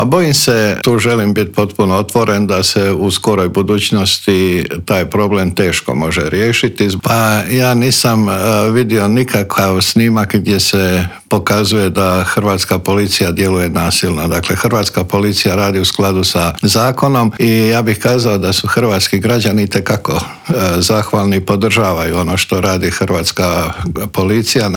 ZAGREB - Gostujući u Intervjuu tjedna Media servisa ministar unutarnjih poslova Davor Božinović komentirao je slučaj Daruvarac, rekonstrukciju Vlade kao i nove optužbe na rad pogranične policije kad su u pitanju migranti.